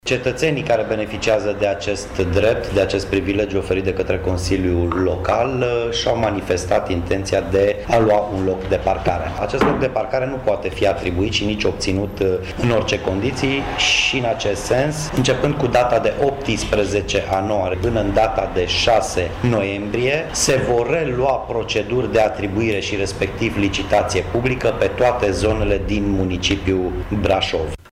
Următorul pas este organizarea unei noi licitații publice, iar întregul proces de atribuire a locurilor de parcare va fi finalizat la începutul lunii noiembrie, după cum a precizat Miklos Gantz, administratorul public al municipiului: